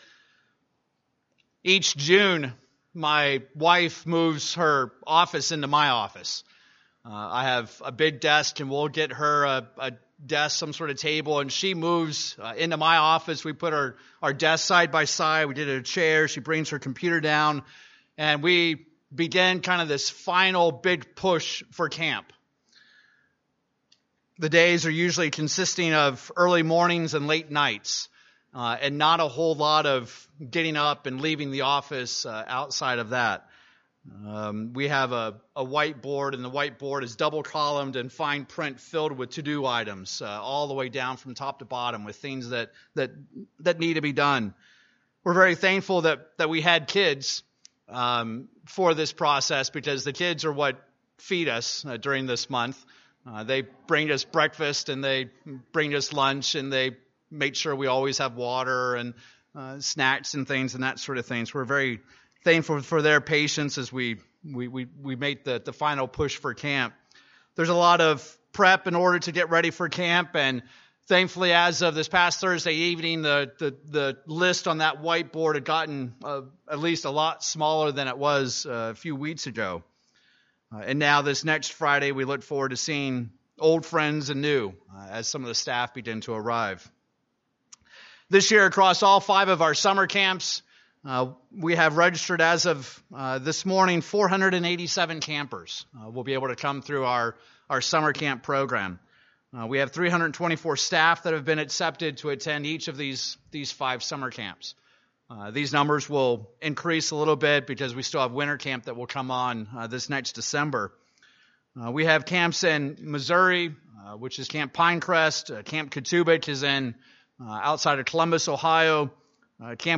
In this sermon we'll discuss God's calling and His working in the lives of our youth, as well as what role the parents play in this special invitation.